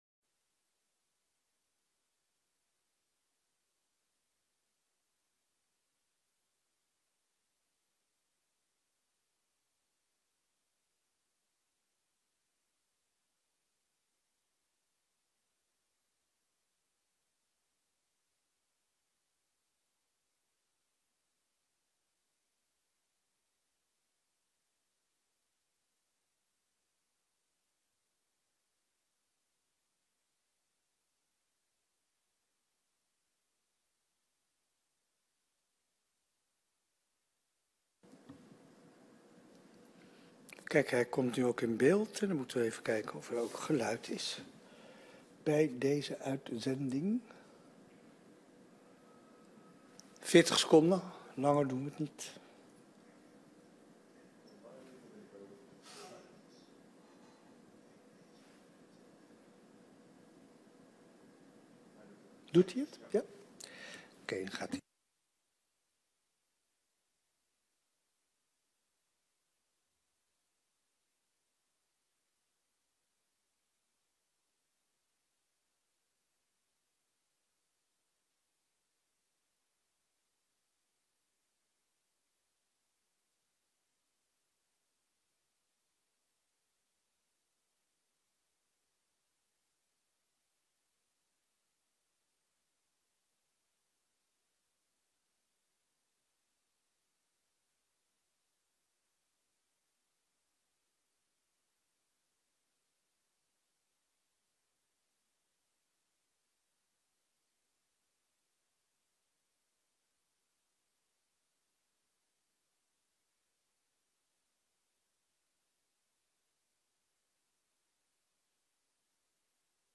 Zitting van het Hoofdstembureau; aansluitendd zitting van het Centraal Stembureau. Deze vergadering stelt definitief vast de uitslag van de verkiezingen voor de gemeenteraad van Haarlemmermeer van 16 maart 2022.